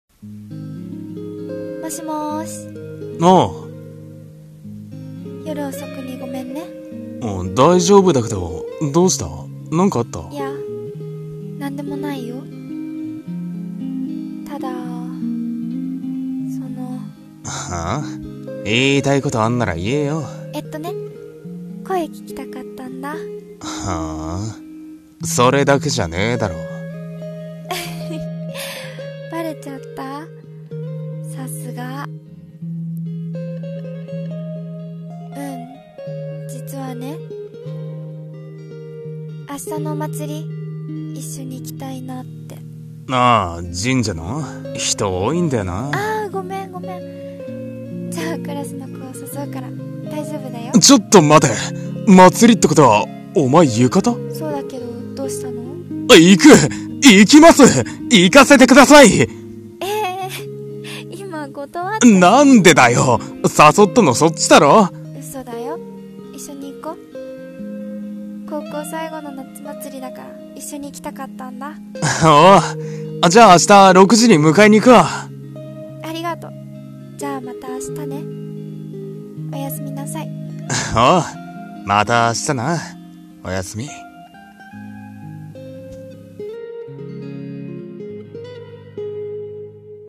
夏祭り行こ？【声劇台本